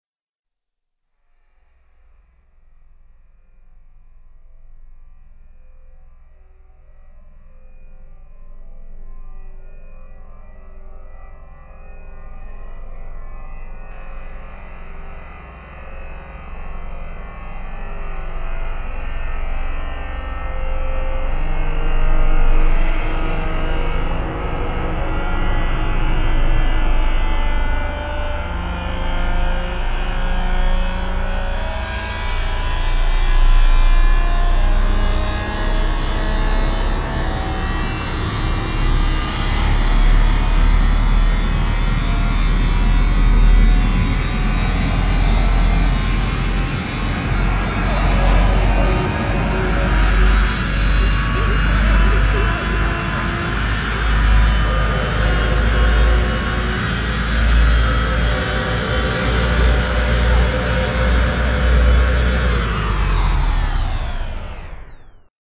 Space Ambient